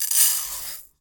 お米を鍋に入れ３
put_rice_in_pot3.mp3